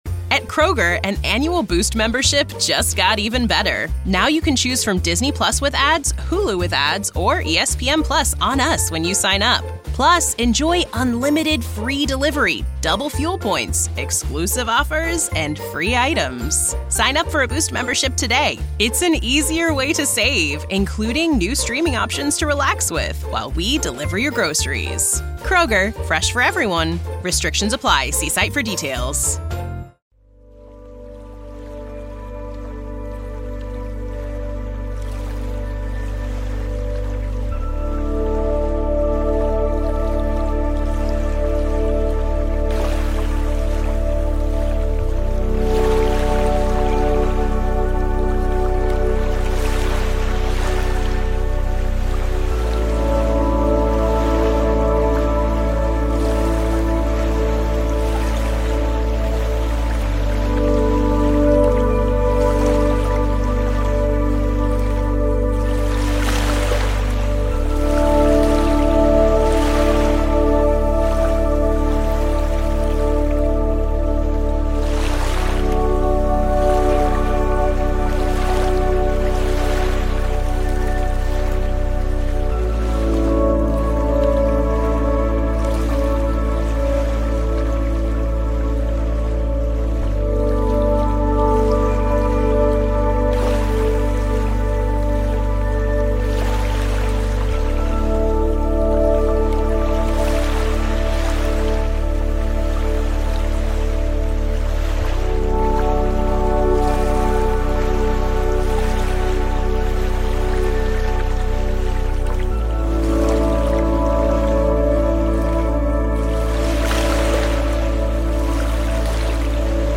The long-awaited rubbing of wheat with your fingers is like using your nerves as strings, a lot of fun and a different sense of wonder.